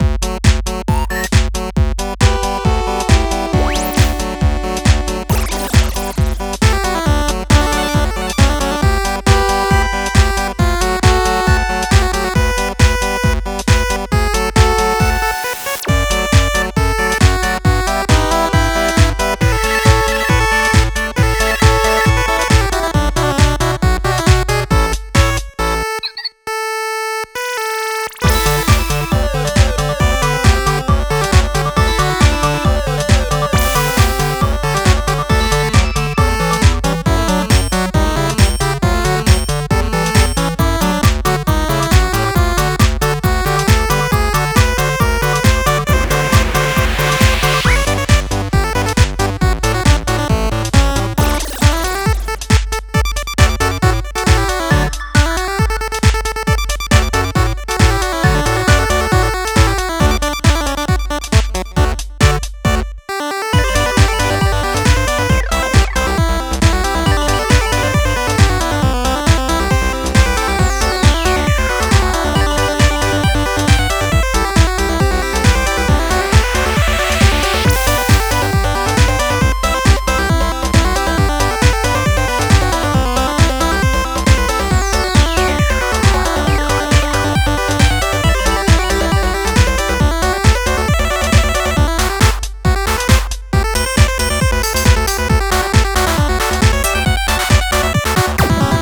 ◆ジャンル：Chiptune/チップチューン